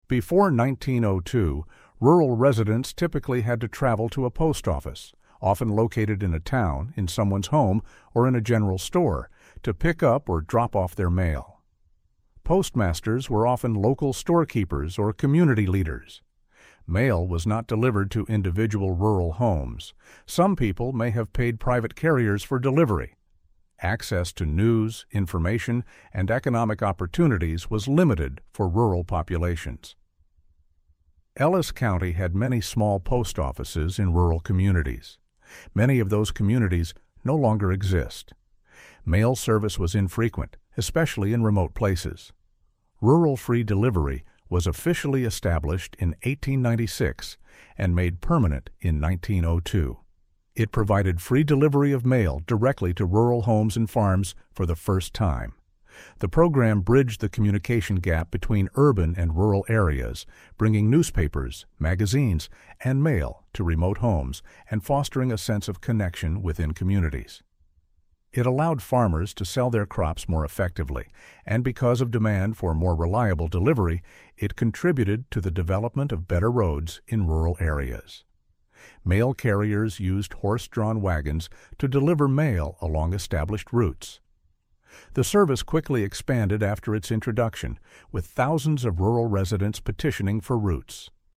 Rural-Free-Delivery-Narrative-Audio.mp3